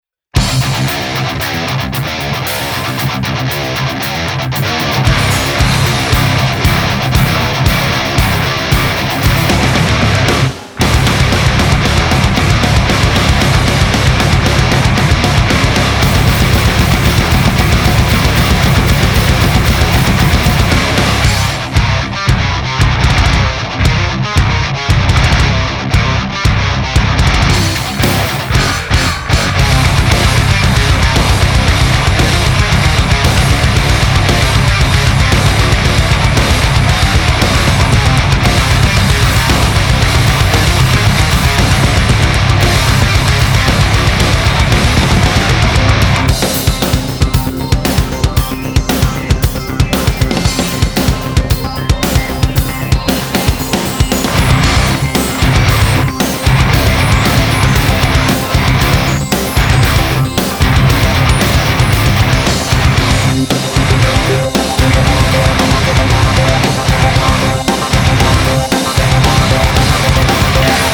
это хайгейн, по-собраннее хот рода, можно рубить и без грелки все виды металла.
хотя и на хот роде (не плюс) можно было мясо сотворить -